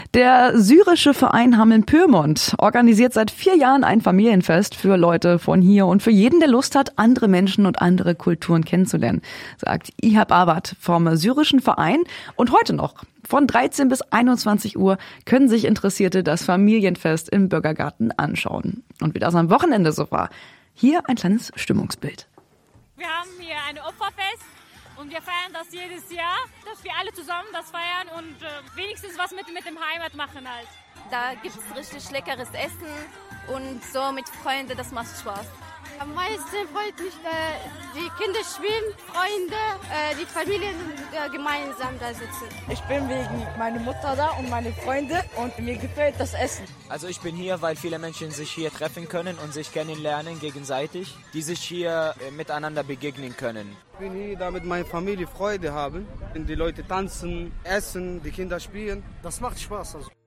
Landkreis Hameln-Pyrmont: SYRISCHES FAMILIENFEST UMFRAGE
landkreis-hameln-pyrmont-syrisches-familienfest-umfrage.mp3